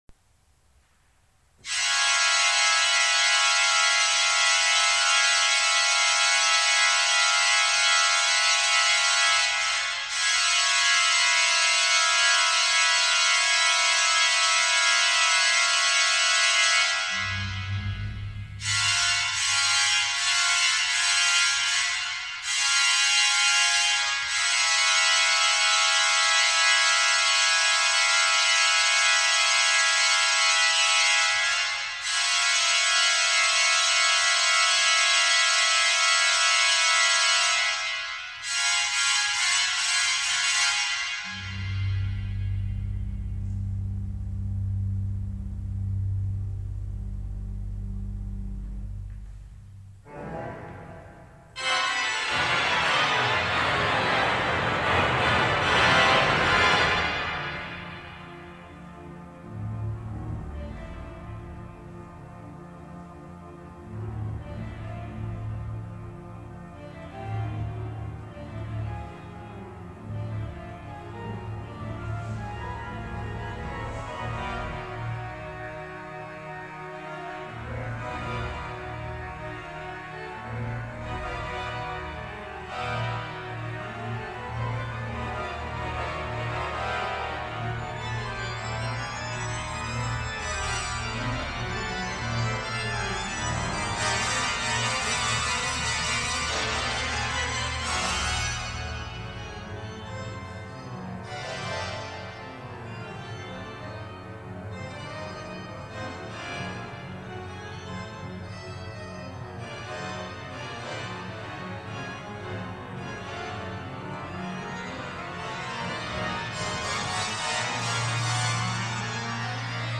für Orgel 오르겔을 위한